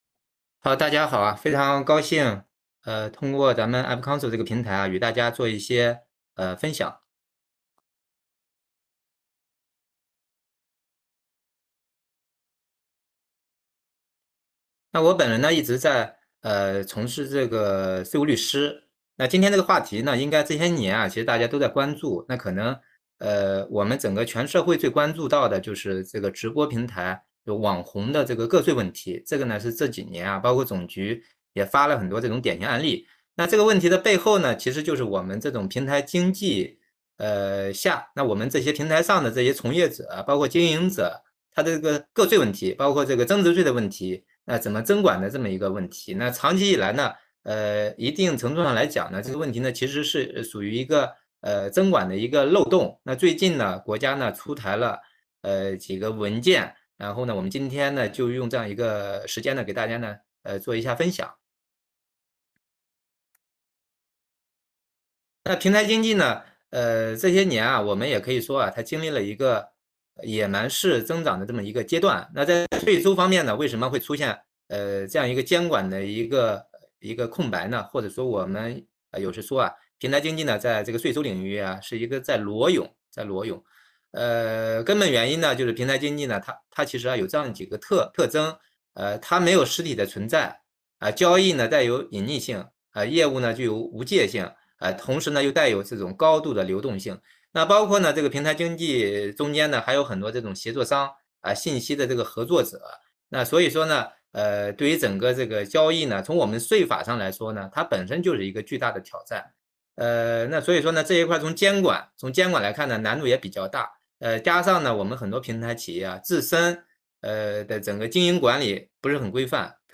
视频会议
互动问答